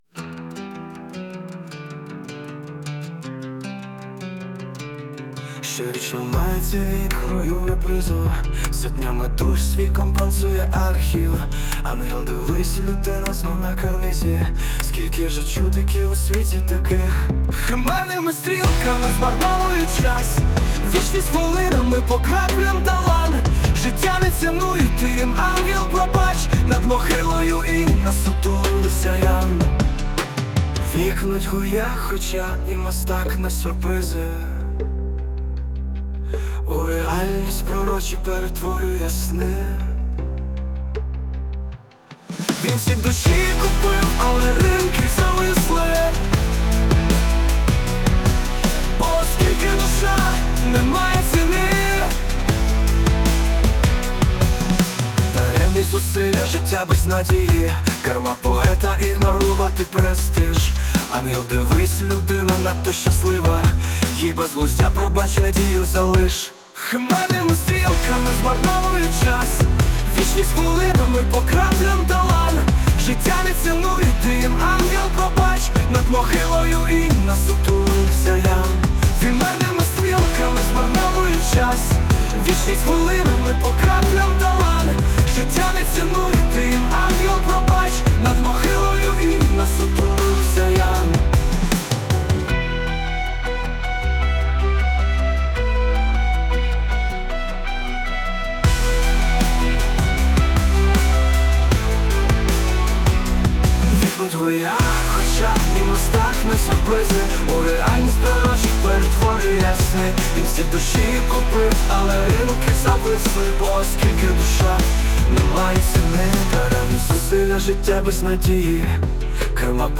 Музика та голос =SUNO
СТИЛЬОВІ ЖАНРИ: Ліричний
ВИД ТВОРУ: Пісня